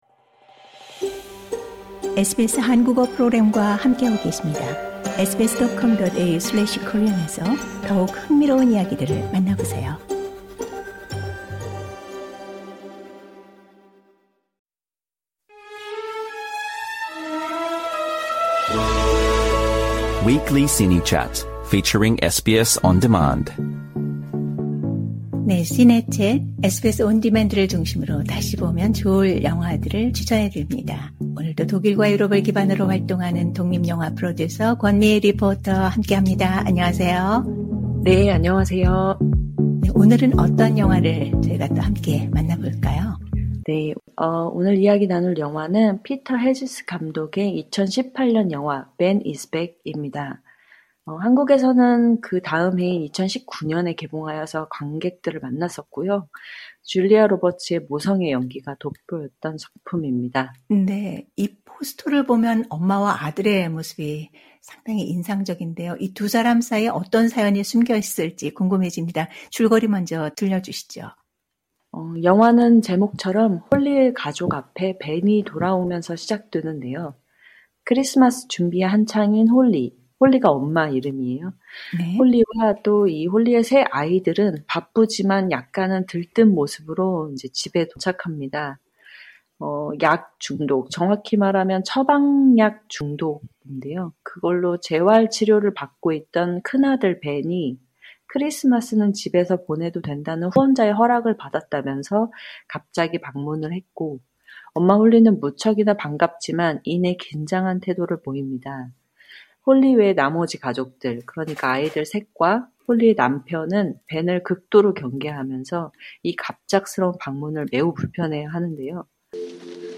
Trailer Audio Clip